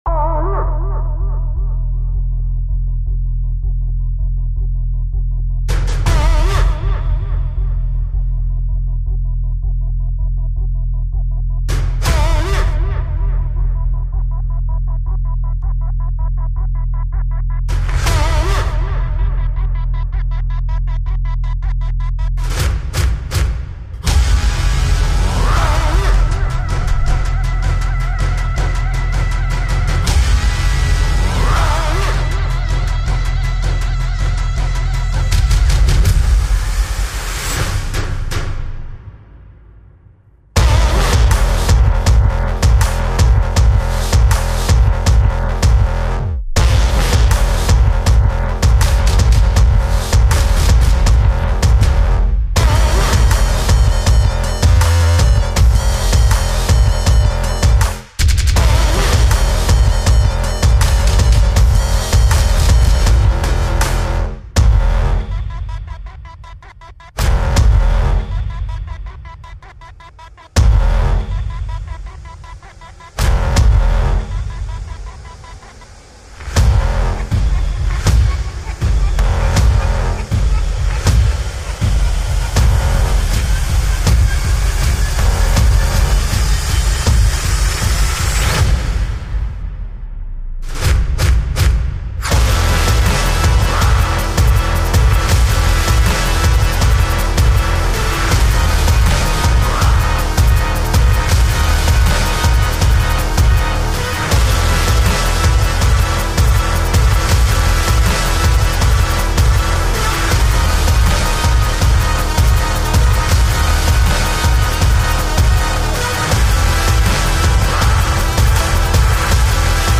Бас-синты и библы для хардстайла.
Ребя, подскажите, где наковырять или как накрутить таких придушенных "носовых" басов как в этом примерно треке?